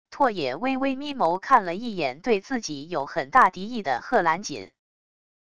拓也微微眯眸看了一眼对自己有很大敌意的贺兰锦wav音频生成系统WAV Audio Player